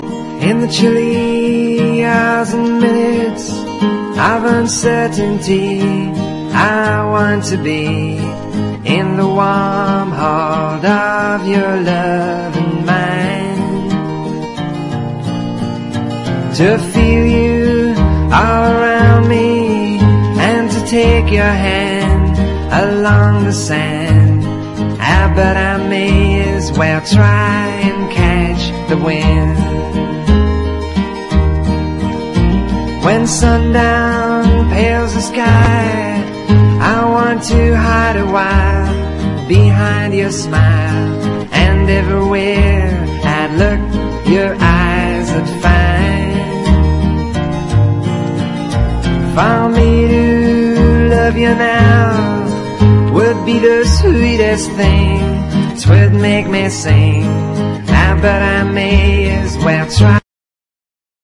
ROCK / 60'S / FOLK / GUITAR / FINGER PICKING
人恋しい潮風が吹く素晴らしい楽曲に聴き入ってください。